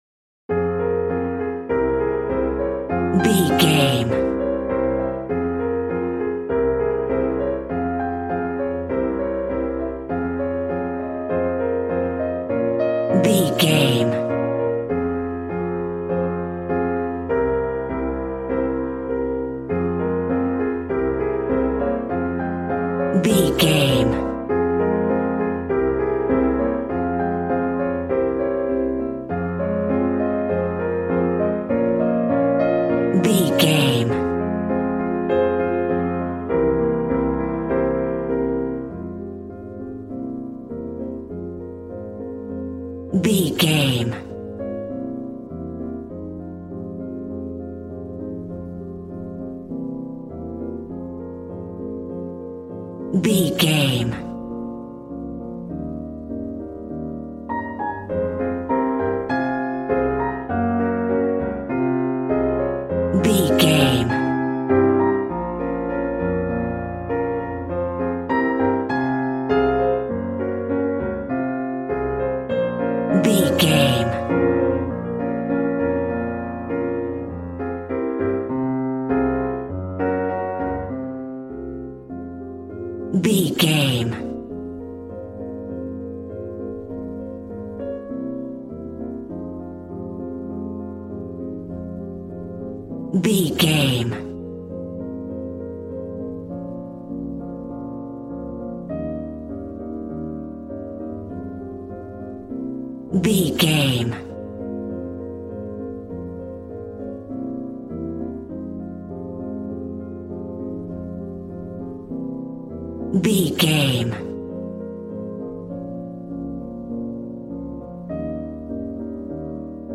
Aeolian/Minor
passionate
acoustic guitar